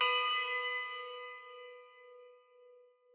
bell1_8.ogg